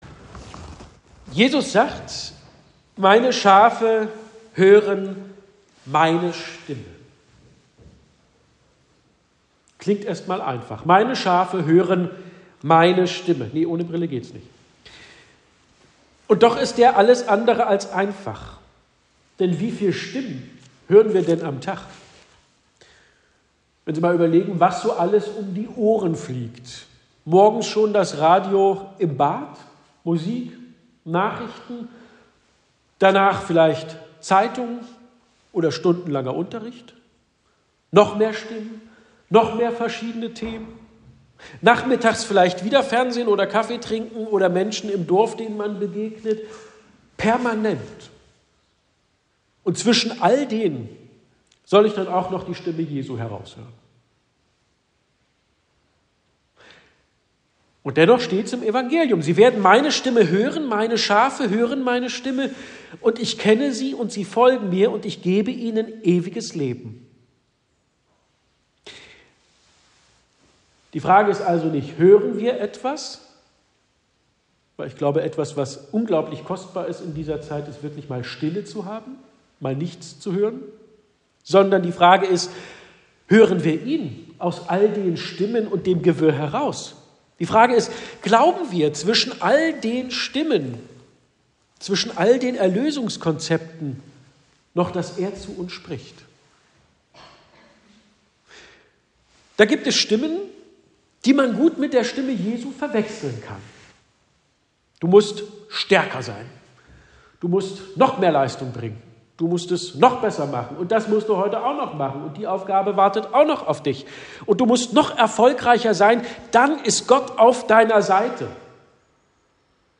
Wer hinhört, merkt: Da geht es nicht um Perfektion, sondern um Orientierung. Die Predigt zum Sonntag Misericordias Domini gibt es jetzt online.